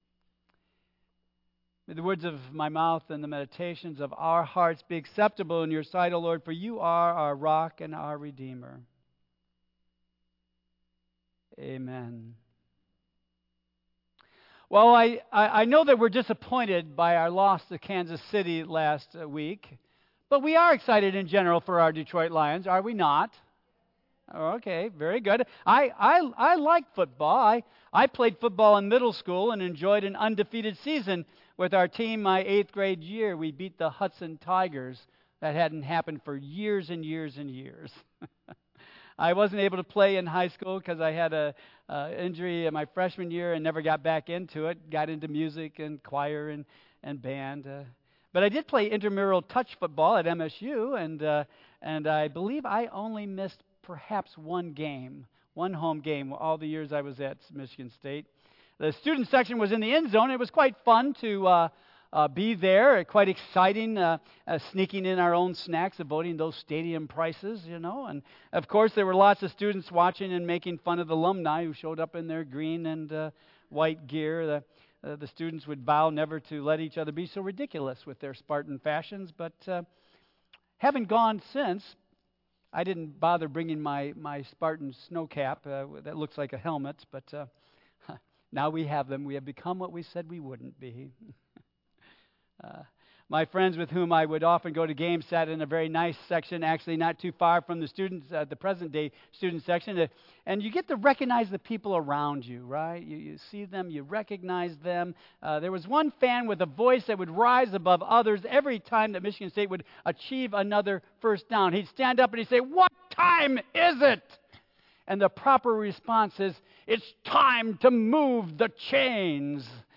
Tagged with Michigan , Sermon , Waterford Central United Methodist Church , Worship Audio (MP3) 7 MB Previous We Gather...To Praise Our God Next We Gather...To Find Comfort